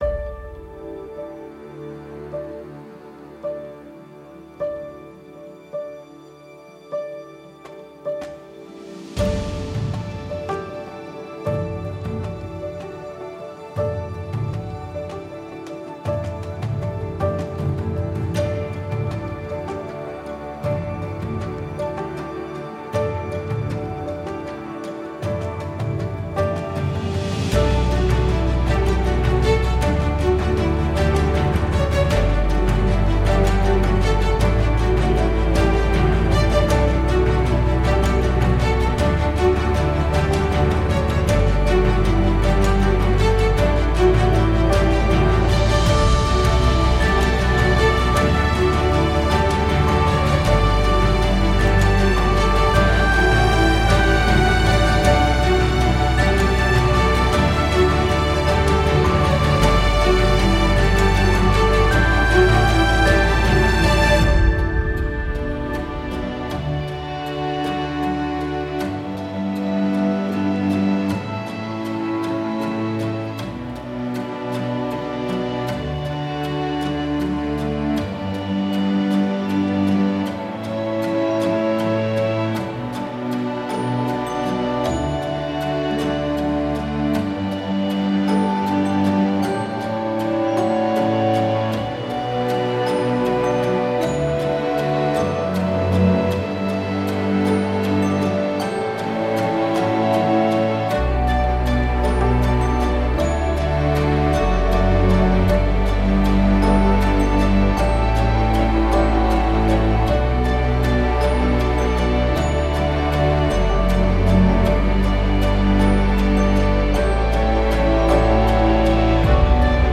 موسیقی اینسترومنتال
آهنگ امبینت